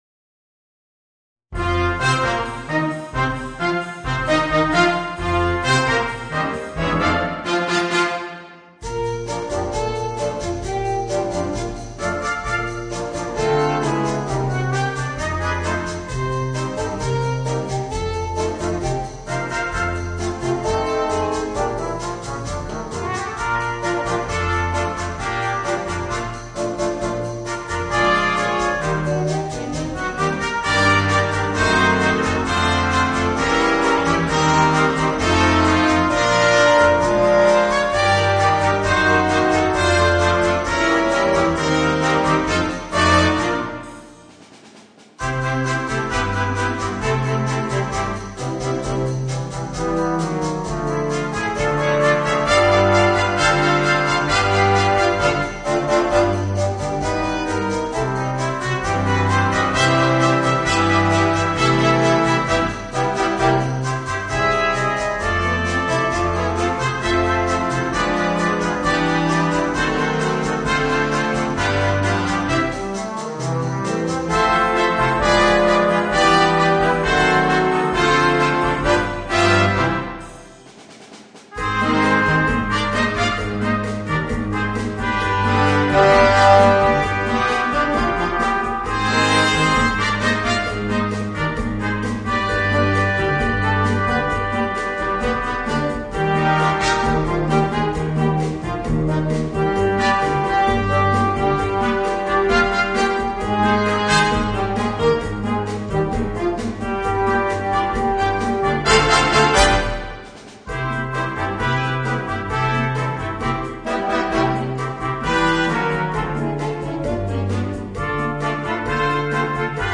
Voicing: Jazz Octet